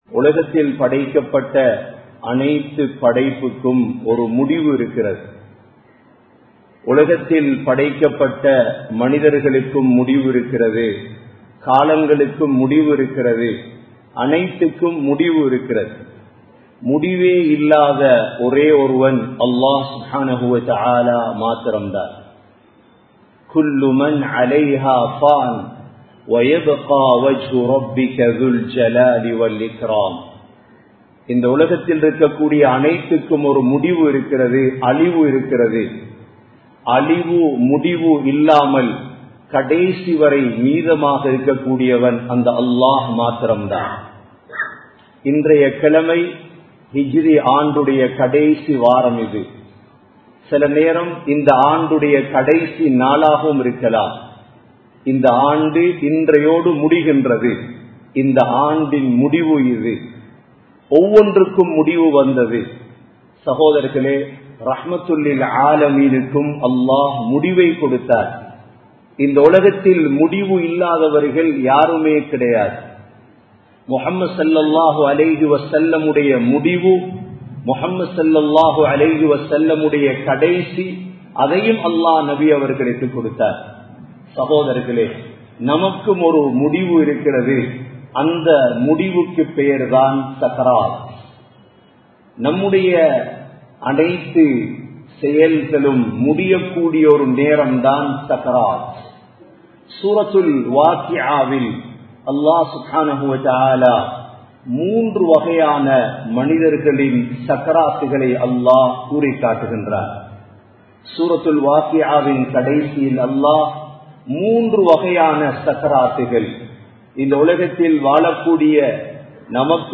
03 Vahaiyaana Sakraath (03 வகையான சக்ராத்) | Audio Bayans | All Ceylon Muslim Youth Community | Addalaichenai
Colombo 11, Samman Kottu Jumua Masjith (Red Masjith)